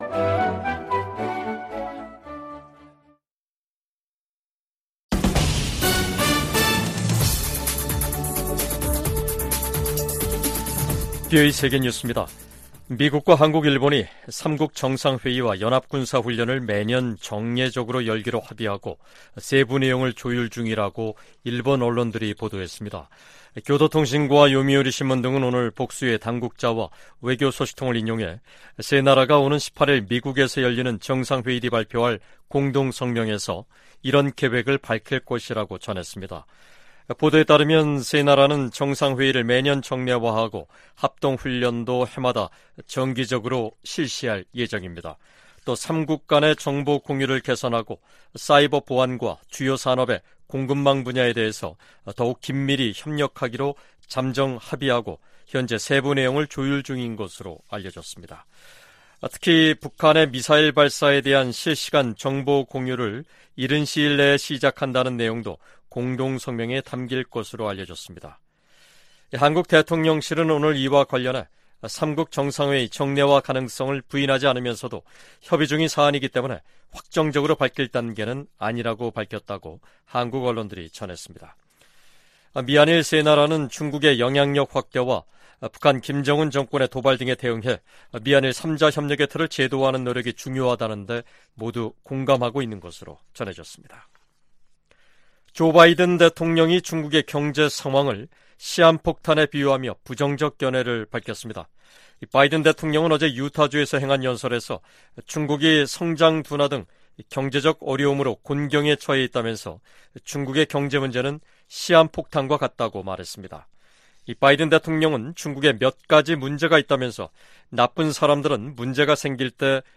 VOA 한국어 간판 뉴스 프로그램 '뉴스 투데이', 2023년 8월 11일 3부 방송입니다. 미국과 한국·일본 등이 유엔 안보리에서 북한 인권 문제를 공개 논의를 요청했습니다. 미 국방부가 북한의 추가 도발 가능성과 관련해 한국·일본과 긴밀하게 협력하고 있다고 밝혔습니다. 북한 해킹조직이 탈취한 미국 내 암호화폐 자산을 동결 조치할 것을 명령하는 미 연방법원 판결이 나왔습니다.